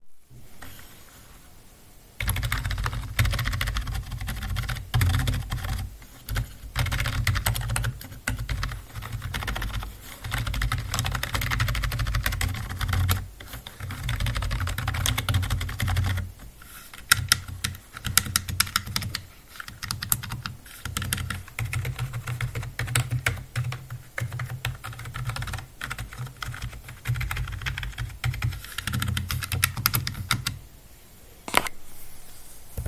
Typ klávesnice membránová, drôtová
CONNECT IT For Health Contrast je štandardná membránová klávesnica s nízkoprofilovými klávesmi a úpravou proti zanášaniu prachom a inými nečistotami.
Môžem potvrdiť, že hlavný blok klávesov je naozaj tichý, ale multimediálne klávesy, šípky, Insert a ostatné a ani číselná klávesnica už tak tiché nie sú.